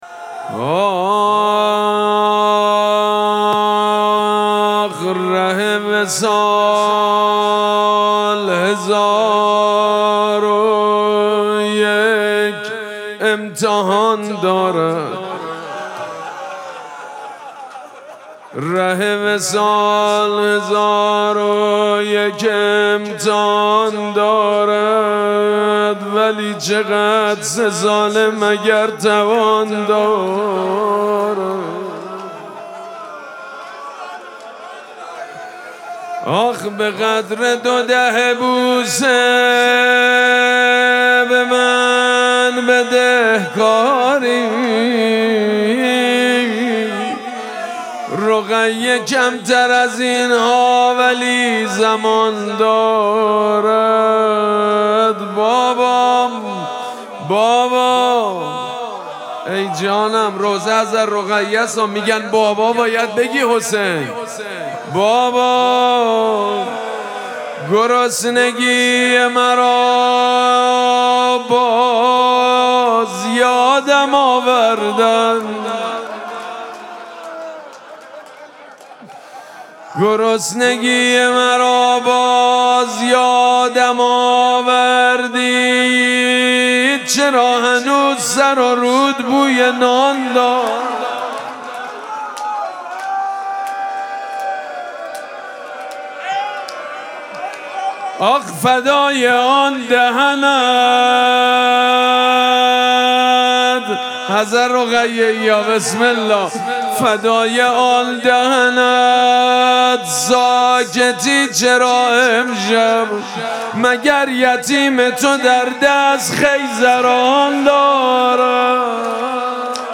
مراسم مناجات شب سوم ماه مبارک رمضان
حسینیه ریحانه الحسین سلام الله علیها
روضه